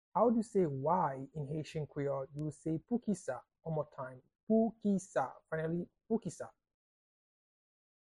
Pronunciation and Transcript:
How-to-say-Why-in-Haitian-Creole-–-Poukisa-pronunciation-by-a-Haitian-teacher.mp3